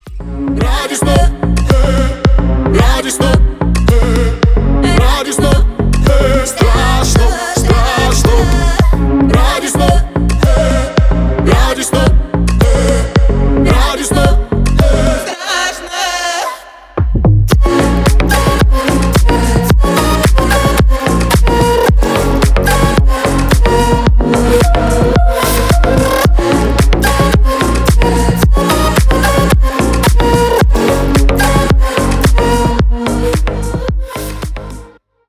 • Качество: 320 kbps, Stereo
Поп Музыка